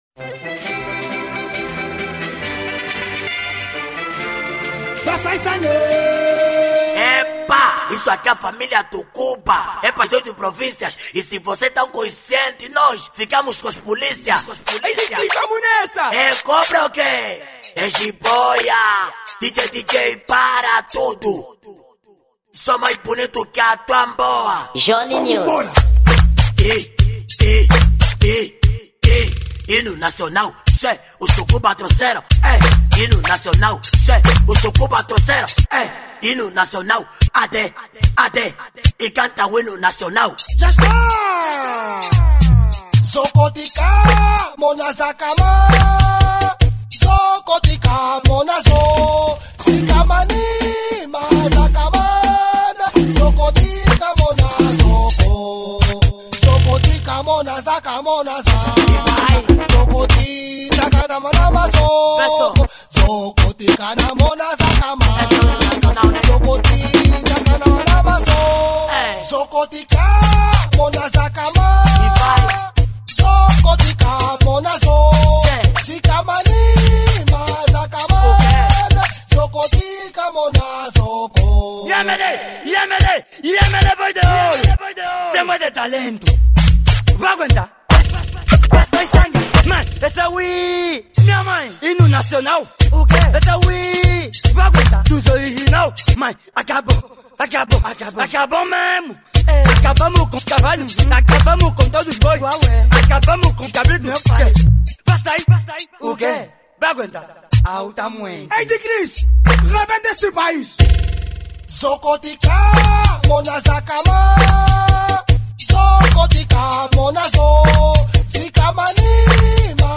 Gênero: Kuduro